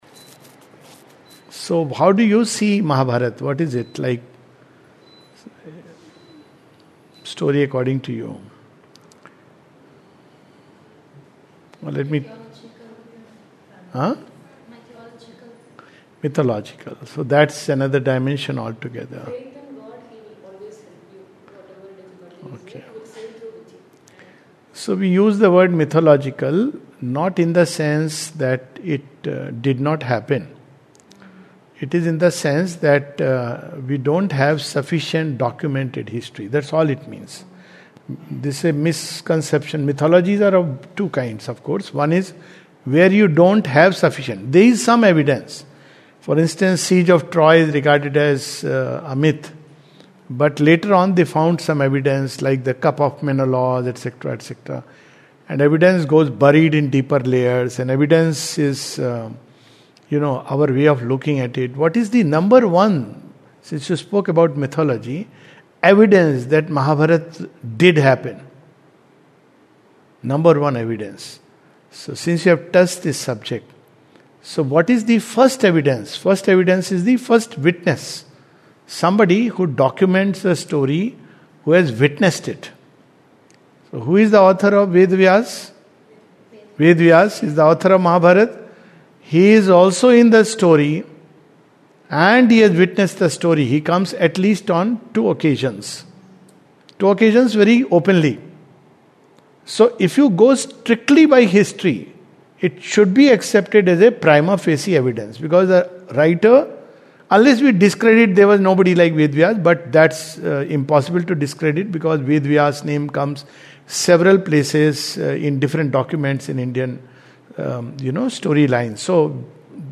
This is a question and answer session at Matriniketan on a few aspects of the Mahabharata (audio only).